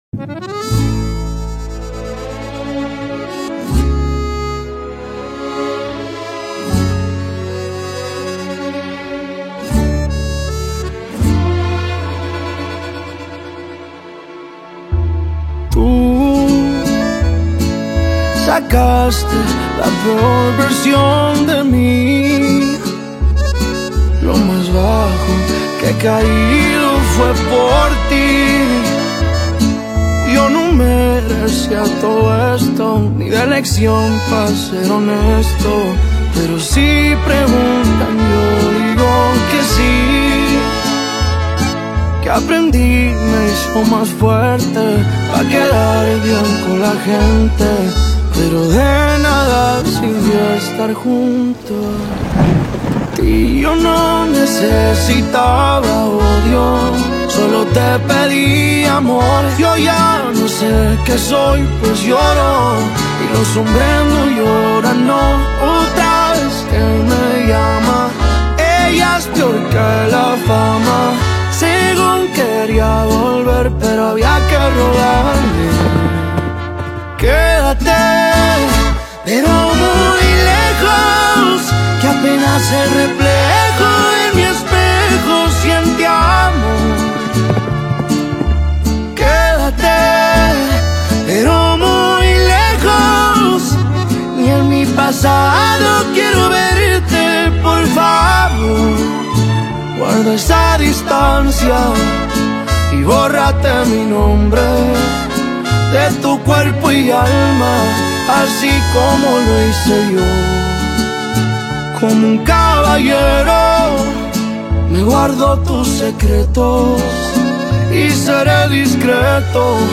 Hip Hop
mesmerizing harmony
a sound that was both robust and innovative